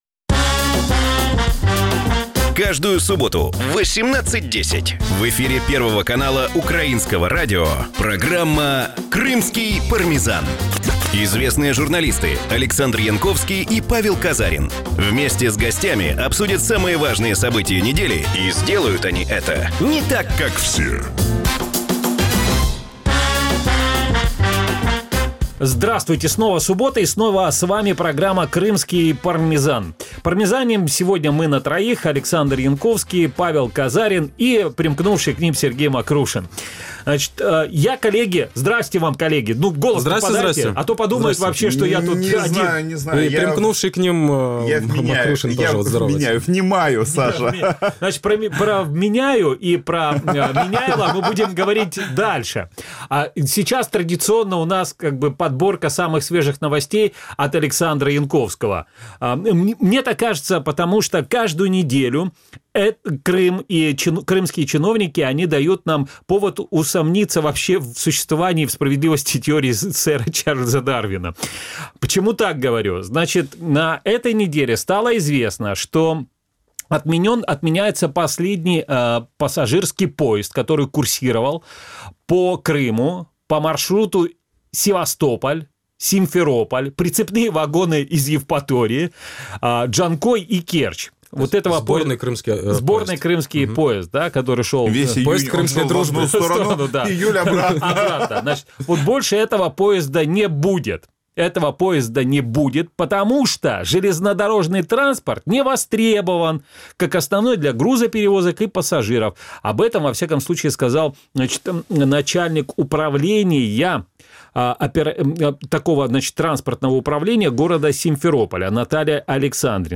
Чому Кремль заслав кримських чиновників подалі від півострова, що означає масово політична рокіровка Кремля і чому Крим перестав бути особливим – відповіді на ці питання в нашій програмі. Програма звучить в ефірі Радіо Крим.Реалії. Це новий, особливий формат радіо.